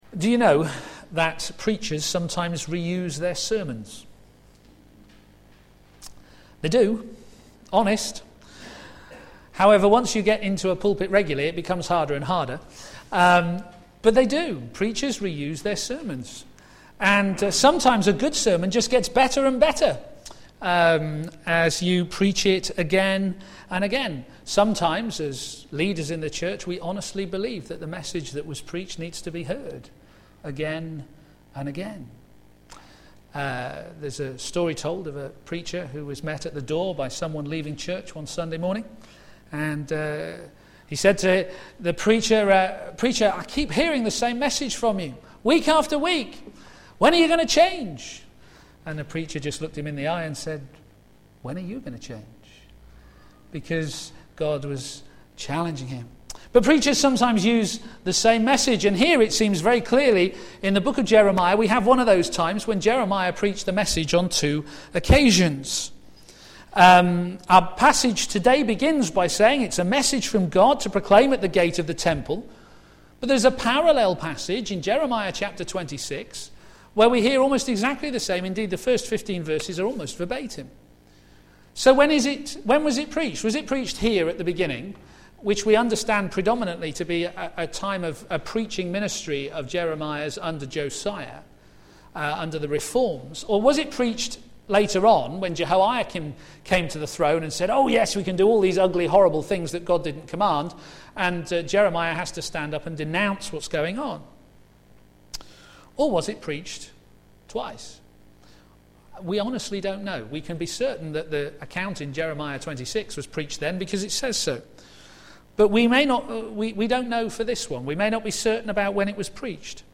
Media for a.m. Service
Sermon